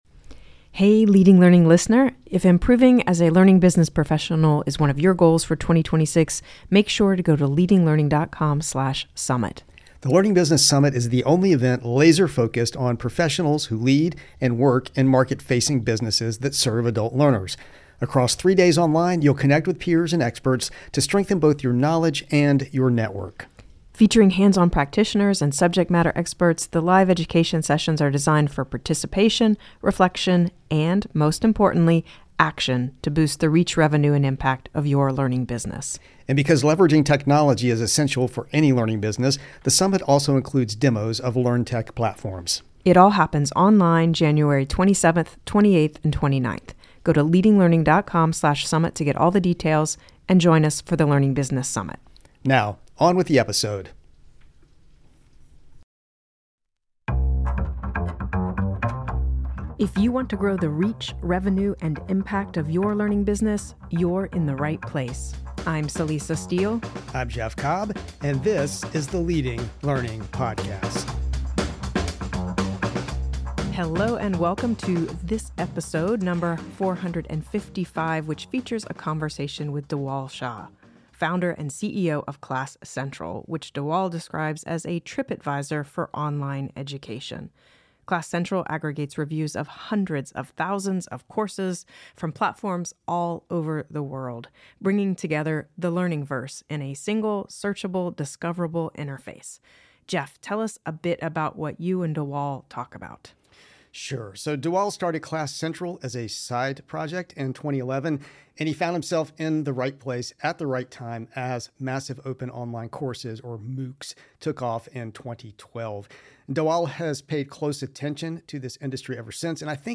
Leading Learning Podcast interviewee